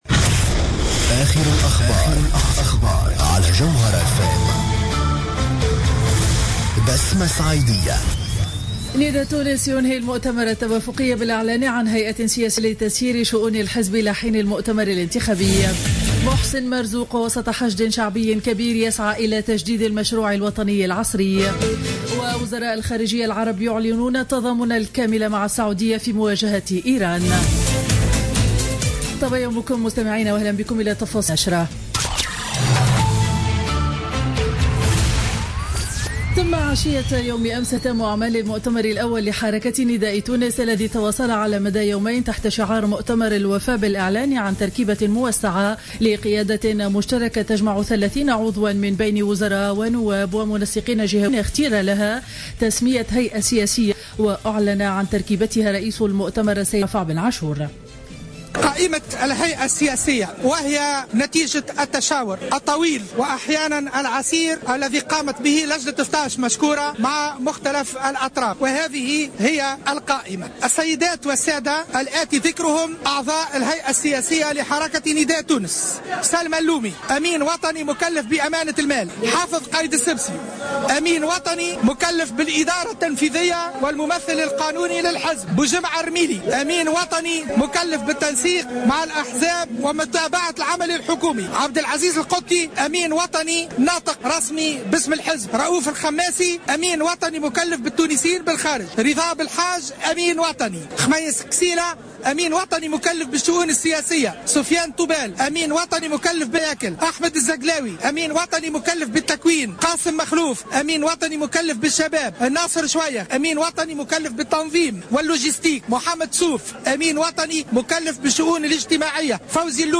نشرة أخبار السابعة صباحا ليوم الاثنين 11 جانفي 2016